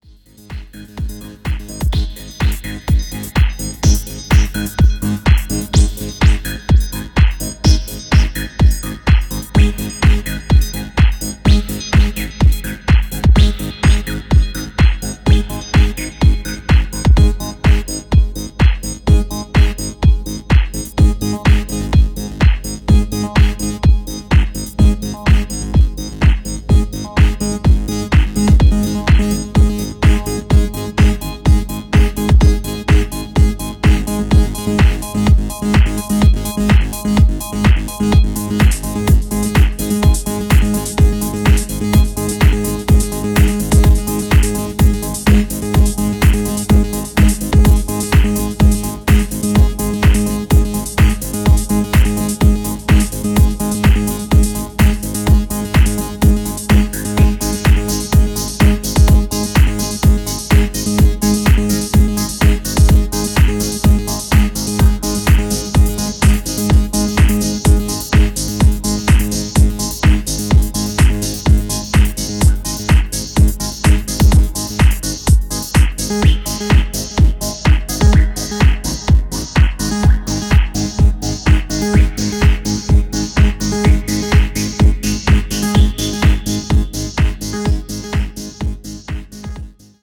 トランシーでプログレッシヴなハウスを程よくミニマルに繰り広げていった、派手さやエグ味は控えめな仕上がりとなっています。